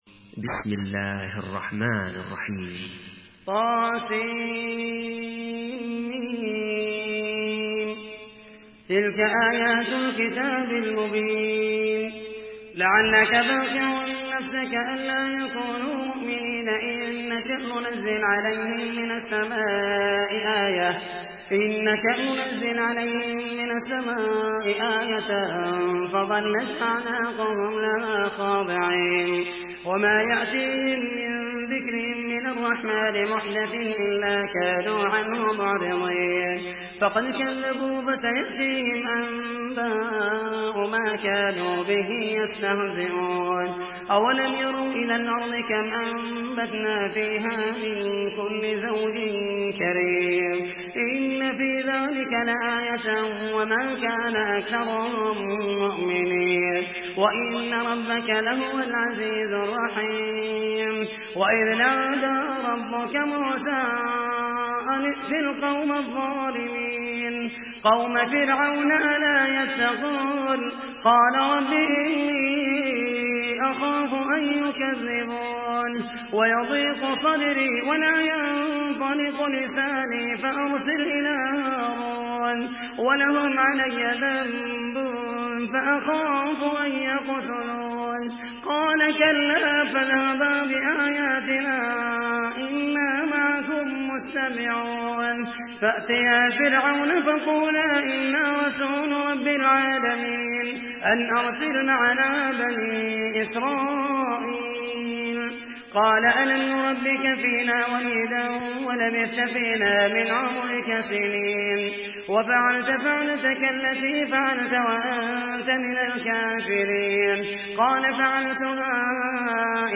Surah Ash-Shuara MP3 by Muhammed al Mohaisany in Hafs An Asim narration.
Murattal Hafs An Asim